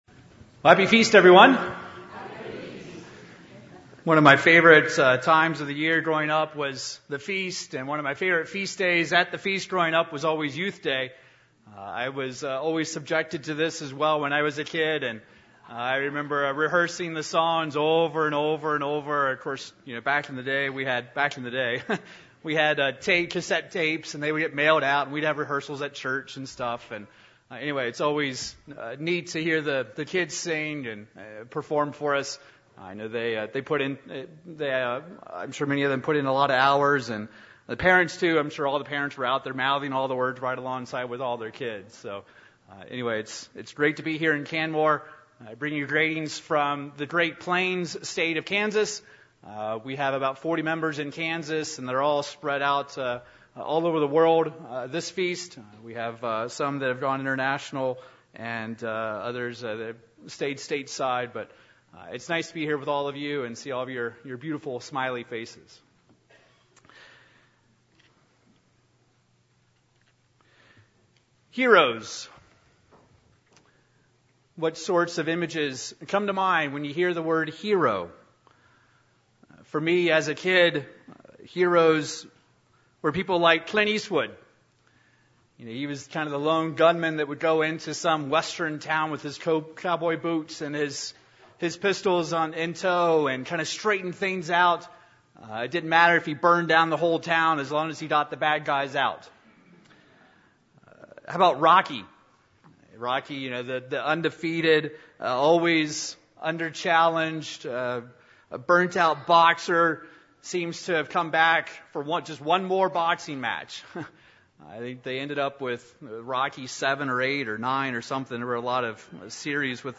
This sermon was given at the Canmore, Alberta 2011 Feast site.